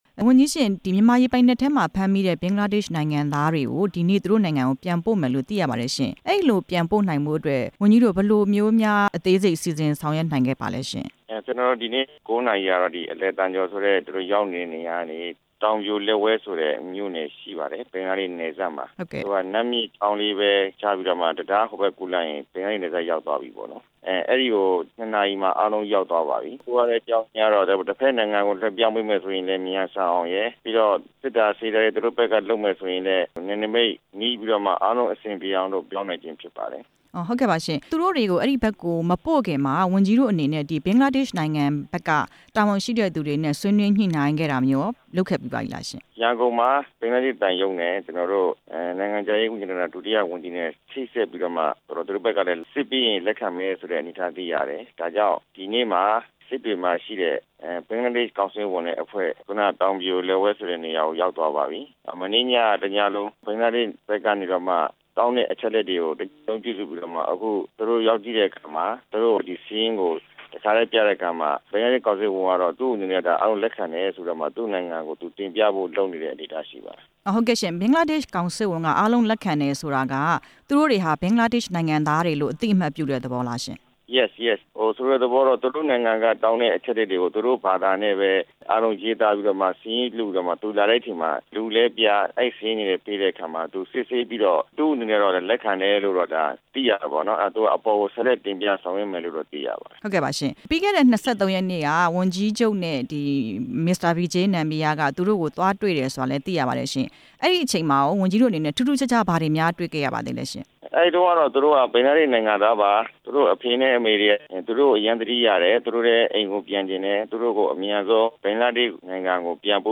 ရခိုင်ပြည်နယ်ဝန်ကြီးချုပ် ဦးမောင်မောင်အုန်းကို မေးမြန်းချက်